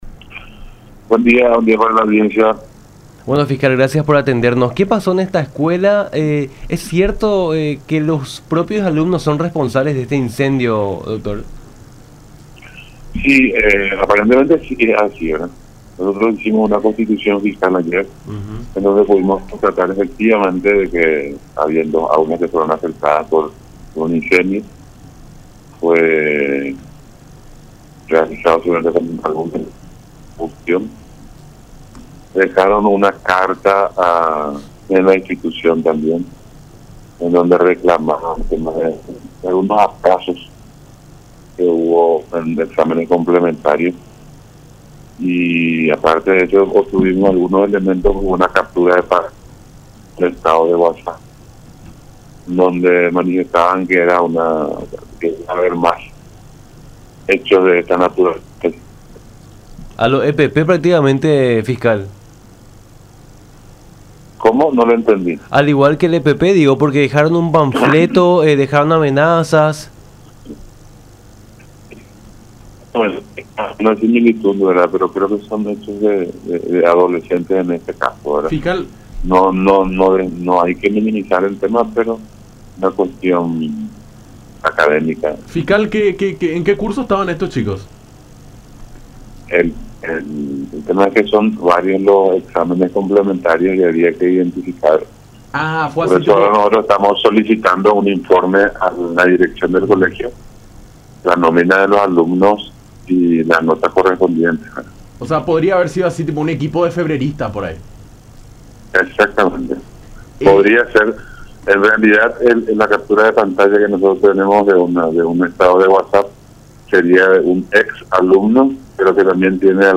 “Son alumnos que tienen que rendir exámenes complementarios”, dijo el fiscal del caso, Éver García, en conversación con Nuestra Mañana por La Unión, en referencia a quienes realizaron la quema.